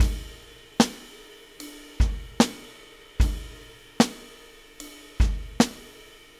Weathered Beat 01.wav